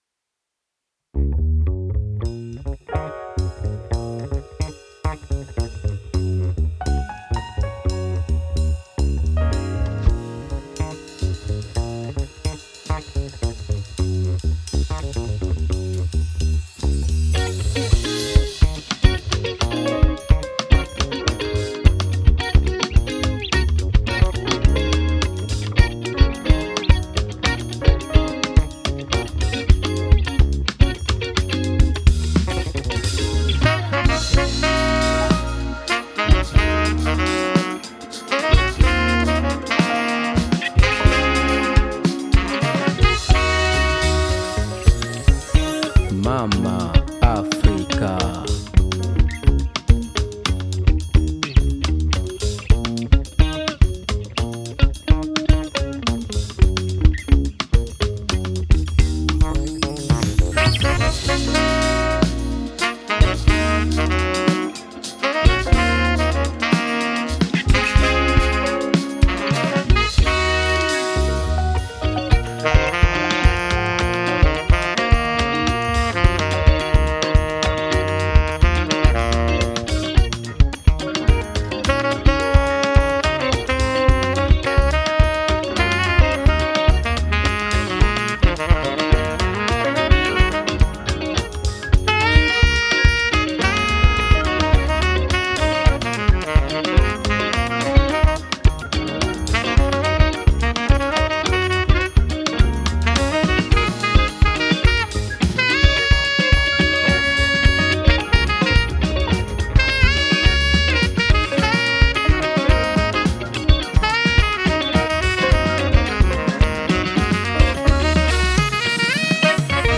qui mêle les sonorités afro-jazz aux influences caribéennes.